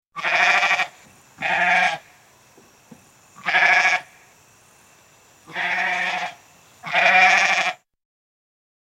Animals
Goat(141K)